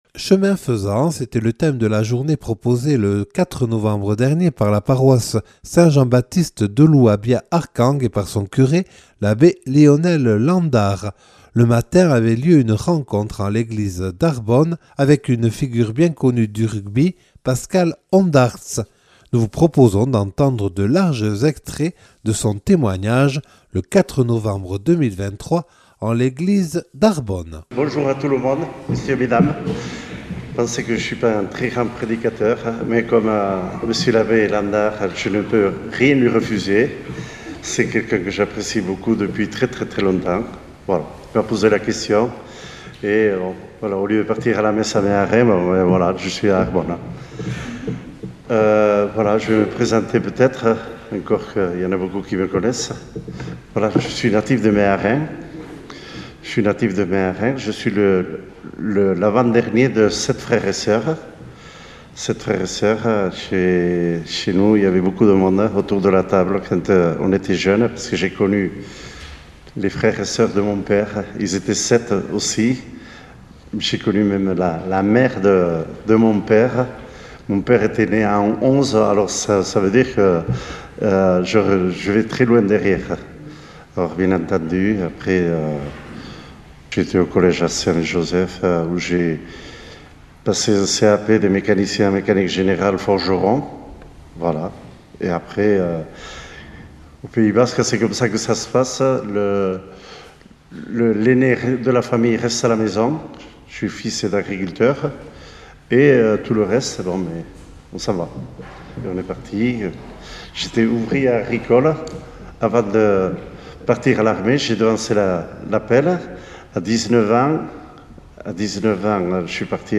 Témoignage de Pascal Ondarts, ancien rugbyman enregistré en l'église d'Arbonne le 4 novembre 2023.